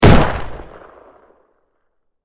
Звуки ружья
Громкий выстрел ружья